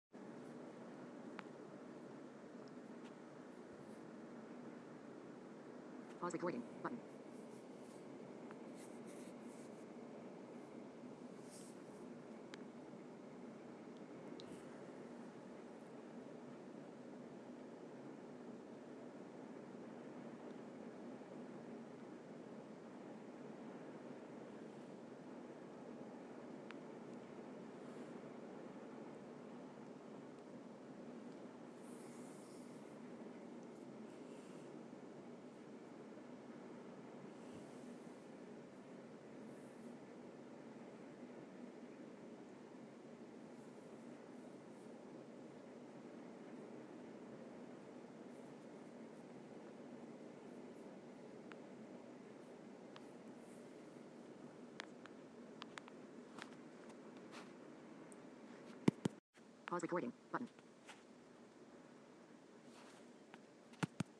Not sure if the recording really picked up any of the sound but this is the sound of the rain from inside our bathroom. The sound was the loudest in here.